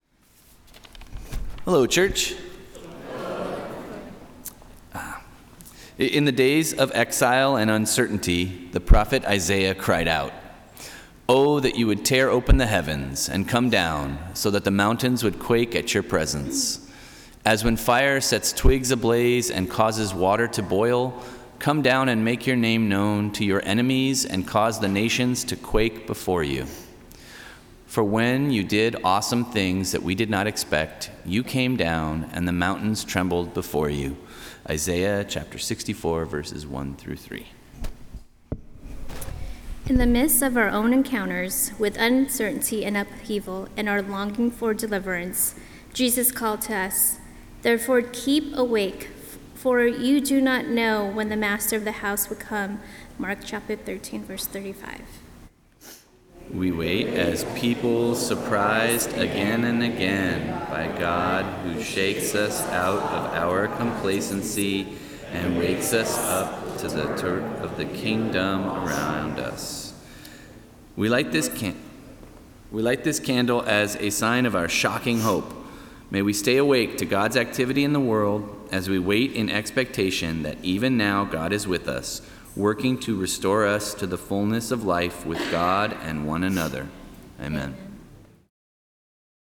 Service of Worship